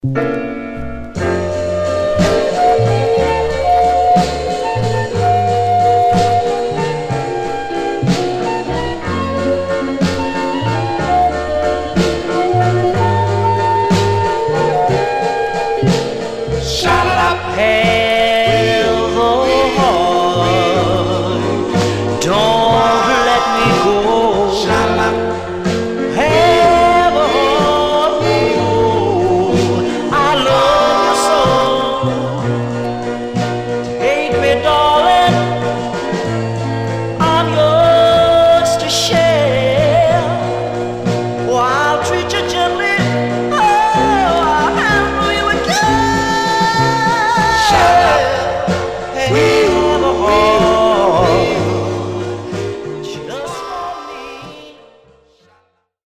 Stereo/mono Mono
Male Black Group